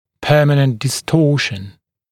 [‘pɜːmənənt dɪ’stɔːʃn][‘пё:мэнэнт ди’сто:шн]необратимая деформация